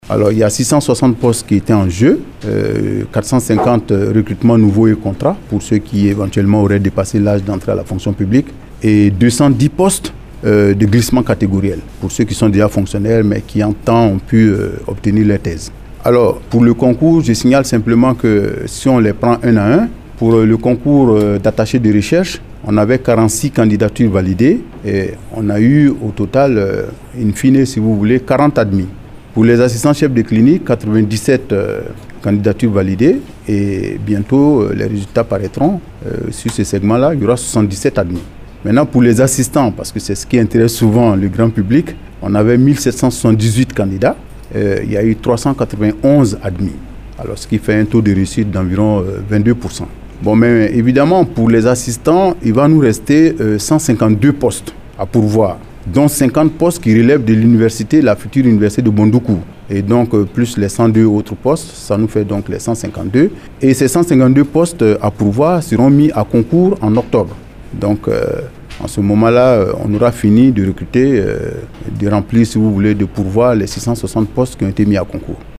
Afin de donner plus de détails sur ces résultats, le Ministre de l’Enseignement Supérieur et de la Recherche Scientifique, a animé une conférence de presse à son cabinet au Plateau, ce vendredi 17 juin 2022.